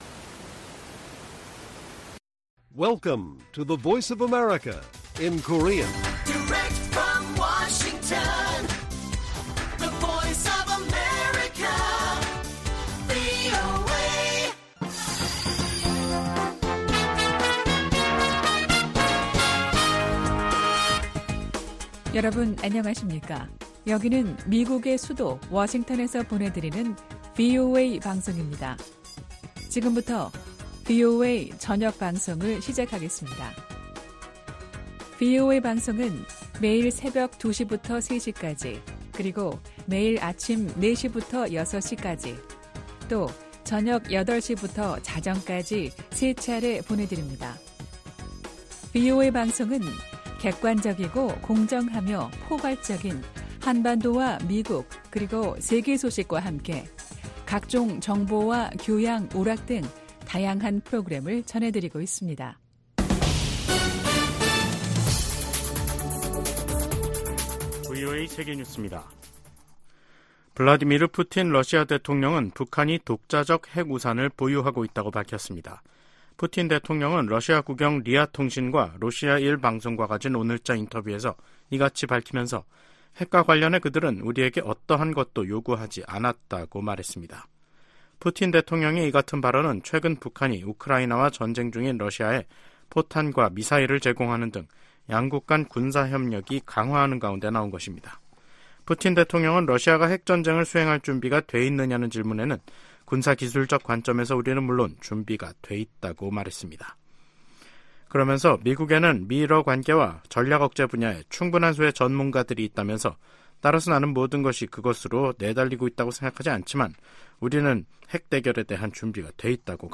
VOA 한국어 간판 뉴스 프로그램 '뉴스 투데이', 2024년 3월 13일 1부 방송입니다. 긴밀해지는 북한-러시아 관계가 김정은 국무위원장을 더 대담하게 만들 수 있다고 애브릴 헤인스 미 국가정보국장이 말했습니다. 북한이 대륙간탄도미사일(ICBM)을 이용해 핵탄두를 미국 본토까지 쏠수 있는 능력을 구축했을 것이라고 그레고리 기요 미 북부사령관이 평가했습니다. 북-러 무기 거래 현장으로 알려진 라진항에서 한 달 만에 대형 선박 입항 장면이 포착됐습니다.